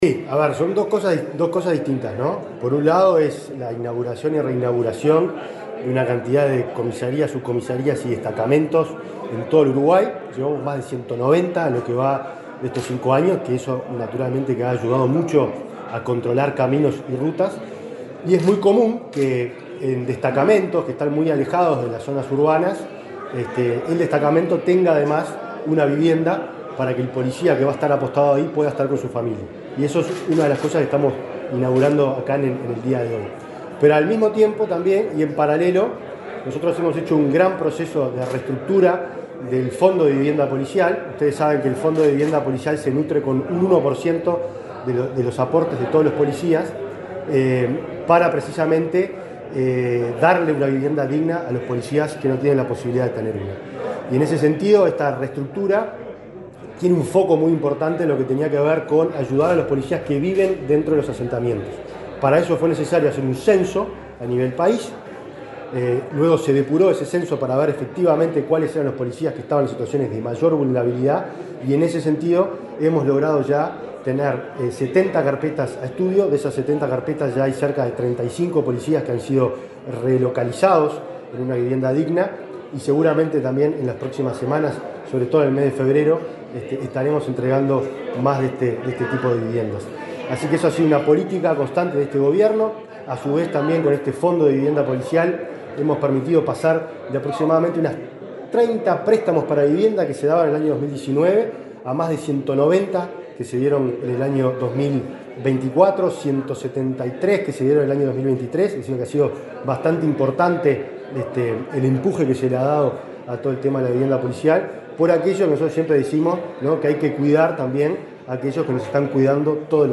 El ministro del Interior, Nicolás Martinelli, dialogó con la prensa, luego de participar de la reinauguración del destacamento de Arroyo de la Virgen,